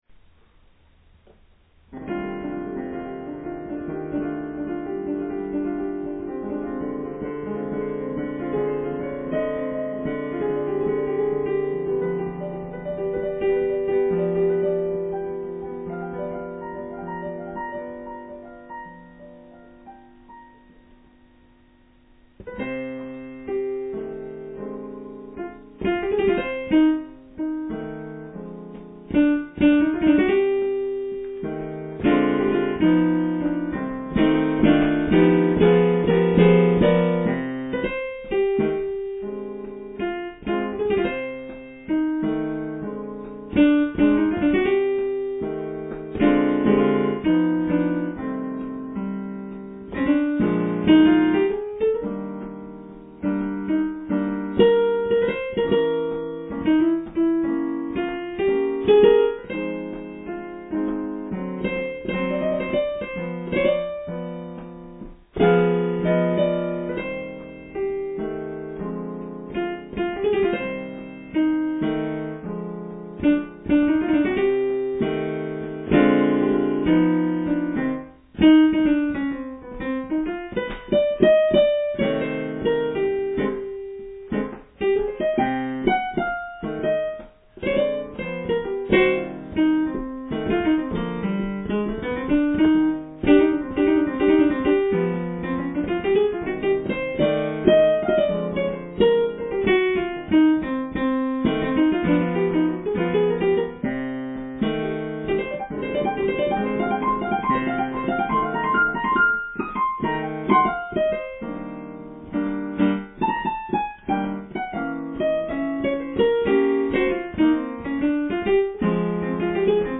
My Piano Trio
repertoire:standard,modern,ballad　＆　bossa